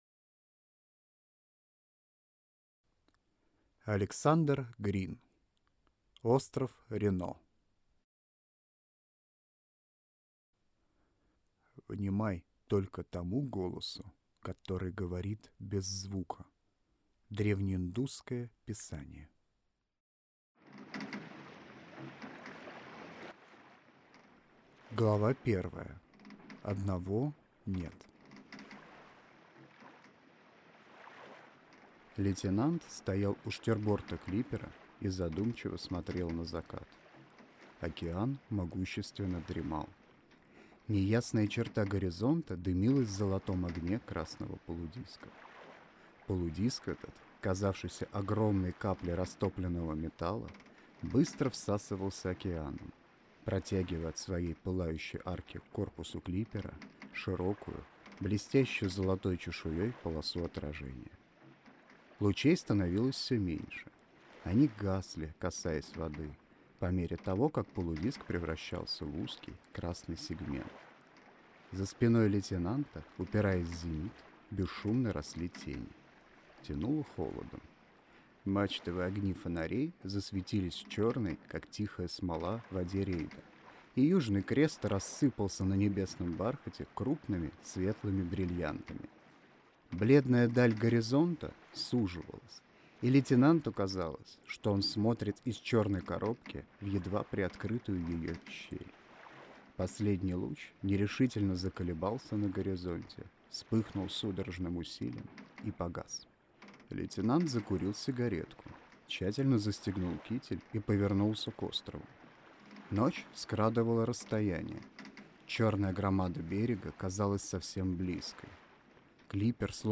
Аудиокнига Остров Рено | Библиотека аудиокниг
Прослушать и бесплатно скачать фрагмент аудиокниги